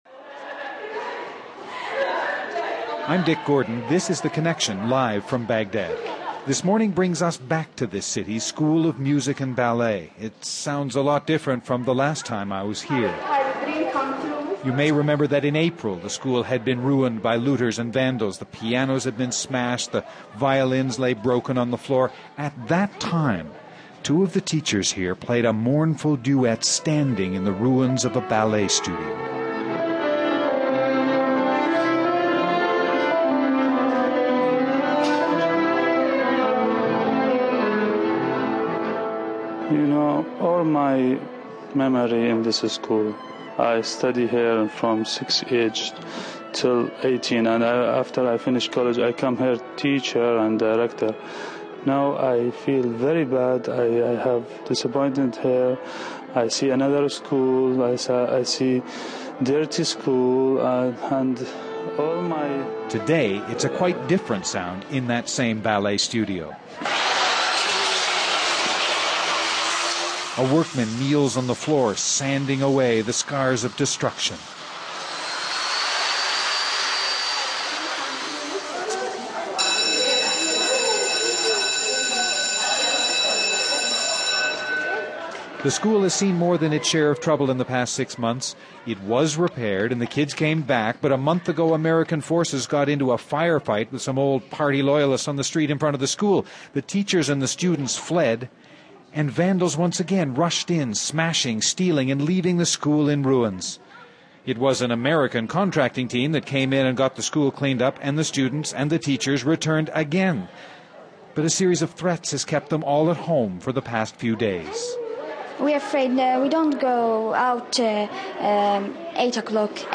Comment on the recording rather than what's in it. Something of a homecoming for us this morning; a return to the school of music and ballet where six months ago we walked with the teachers through the vandalized and looted ruins of their school.